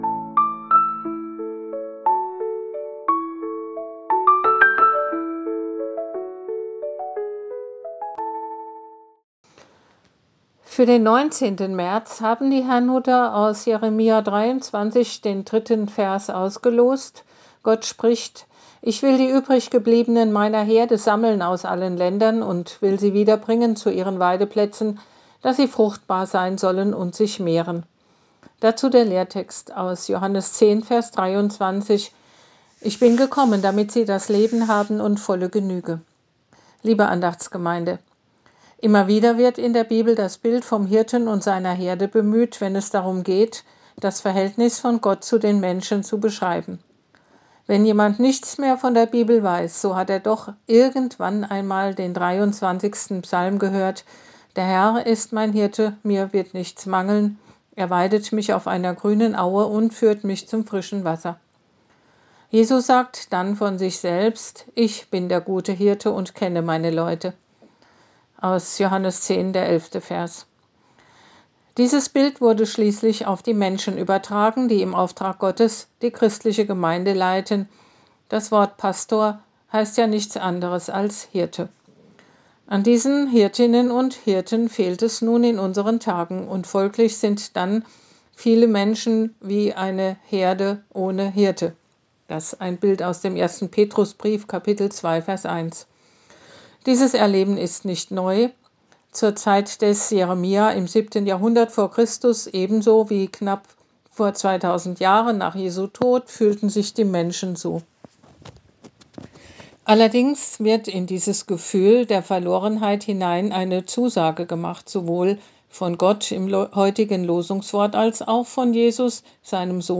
Losungsandacht für Donnerstag, 19.03.2026
Losungsandachten